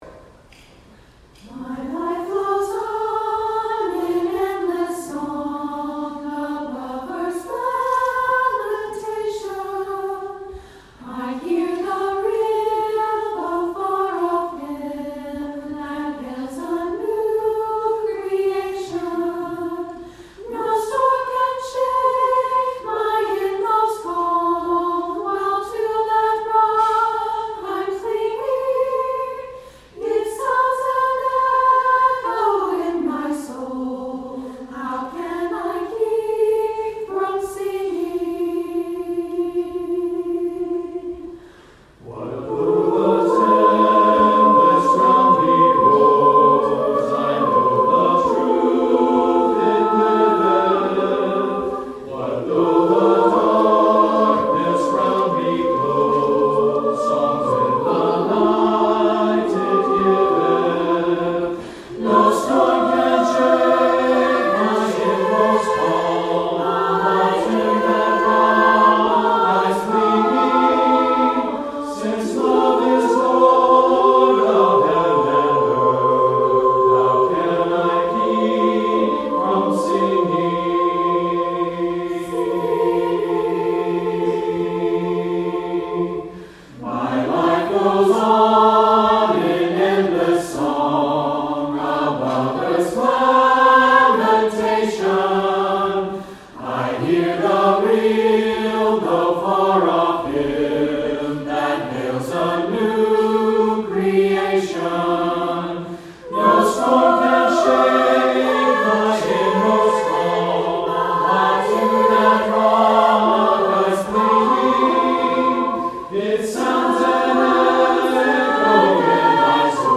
Offertory: Chancel Choir